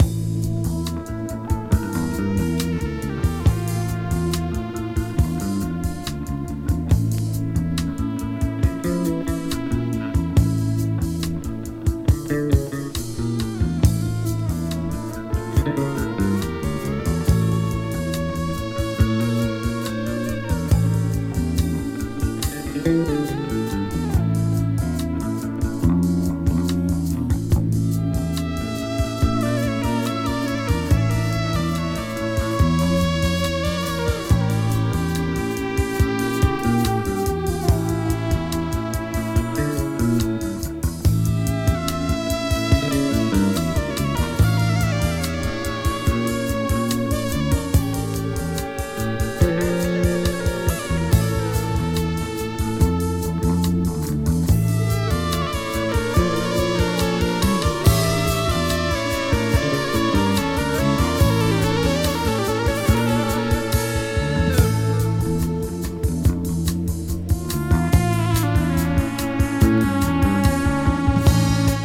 70年代後半のスペインのプログレッシブ・ロックバンド
少しイージーリスニング的なノホノン感が堪らないプログレッシブ・ロック。